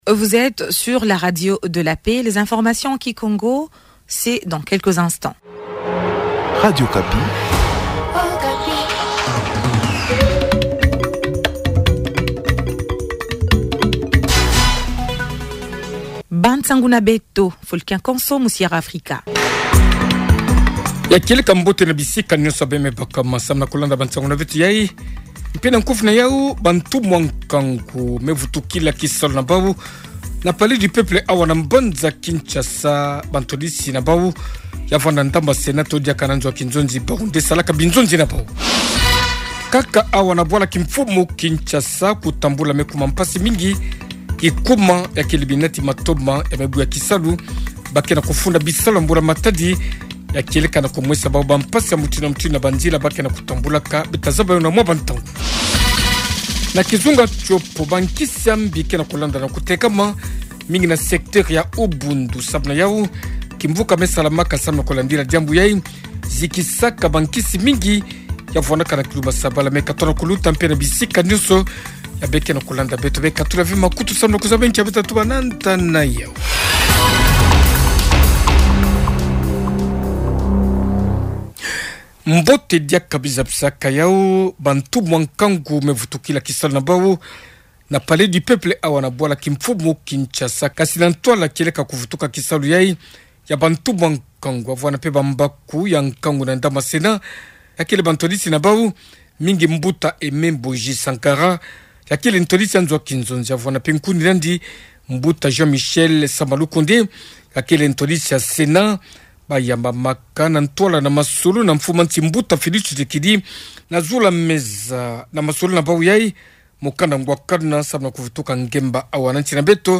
Journal Matin